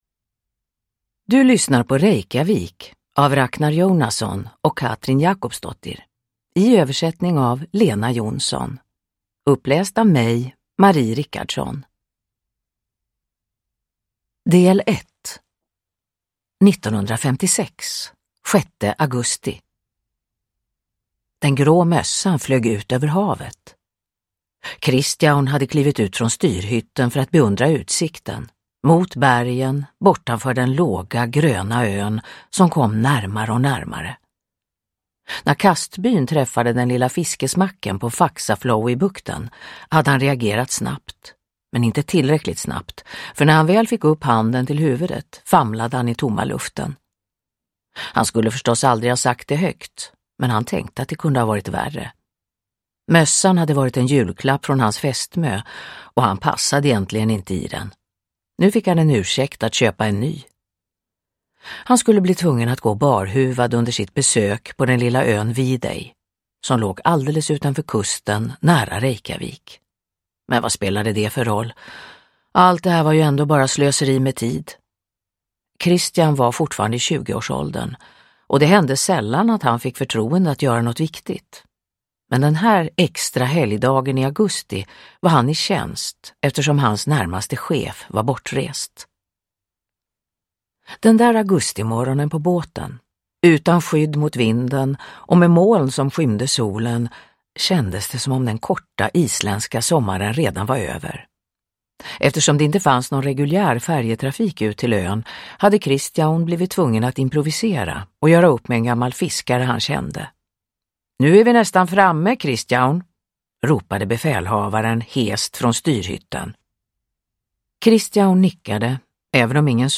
Downloadable Audiobook
Ljudbok